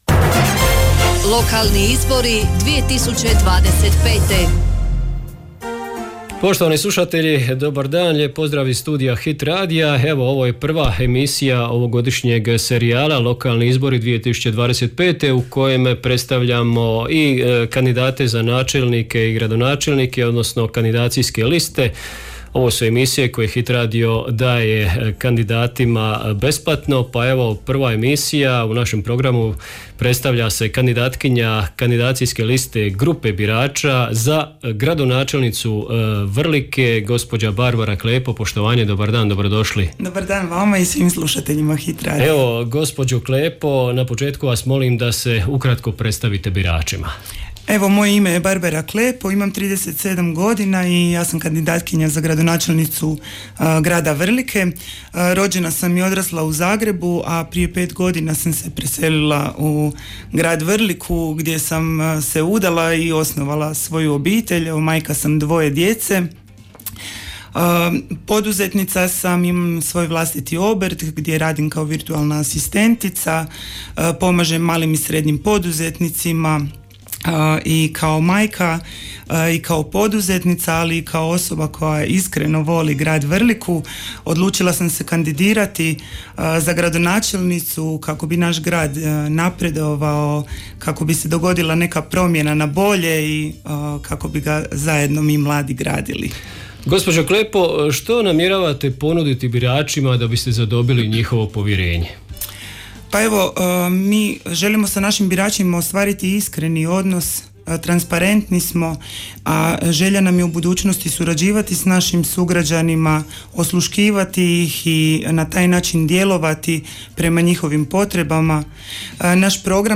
Hit radio prati izbore u 7 jedinica lokalne samouprave (Grad Vrlika, Općina Dicmo, Općina Hrvace, Općina Dugopolje, Općina Otok, Grad Trilj, Grad Sinj). Sve kandidacijske liste i svi kandidati za načelnike odnosno gradonačelnike tijekom službene izborne kampanje imaju pravo na besplatnu emisiju u trajanju do 10 minuta u studiju Hit radija.